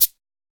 washboard_d.ogg